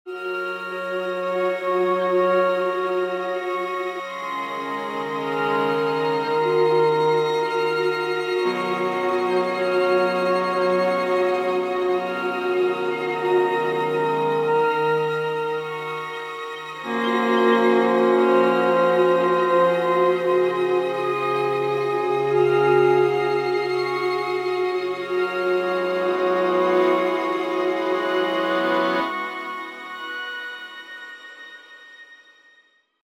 Minimalist sound design for architectural